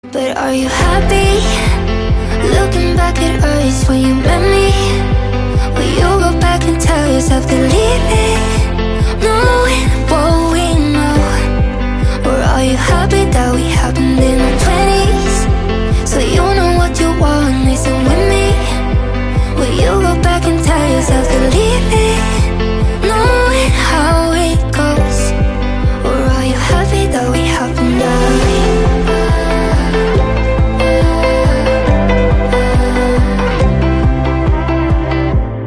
Catégorie POP